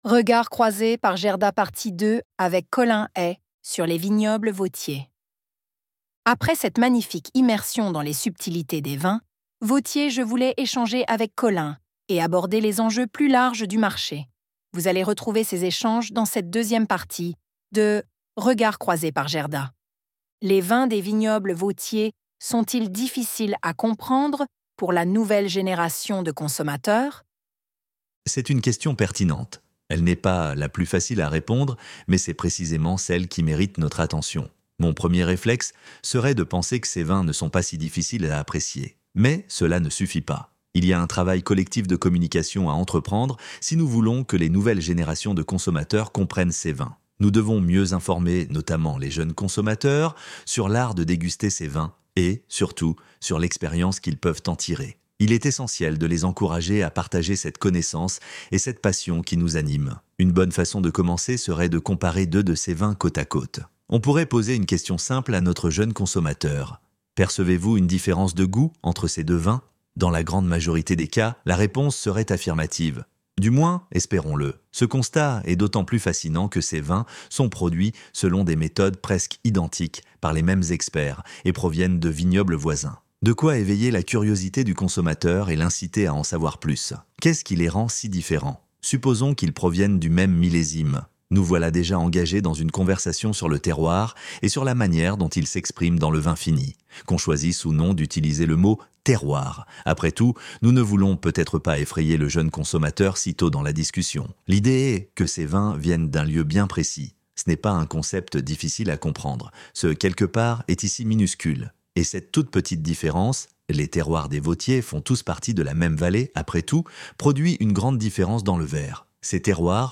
ElevenLabs_traduction_francaise_partie_2.docx.pdf-1.mp3